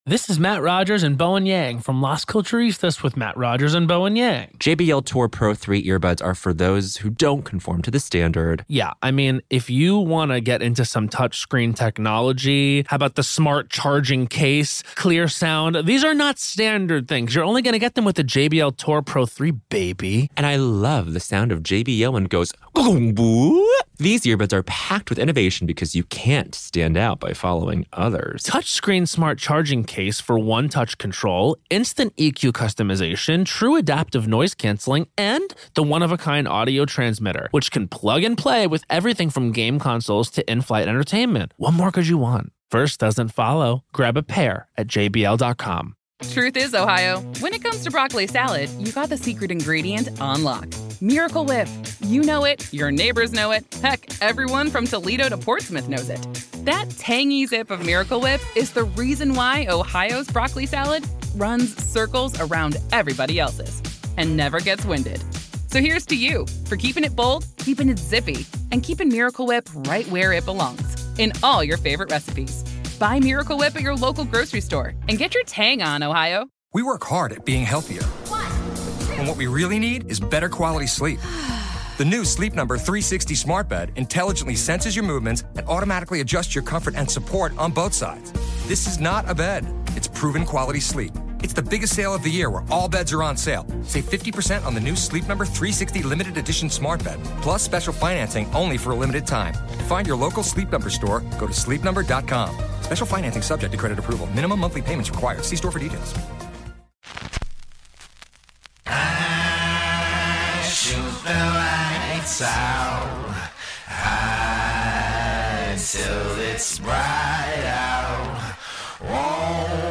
During their interview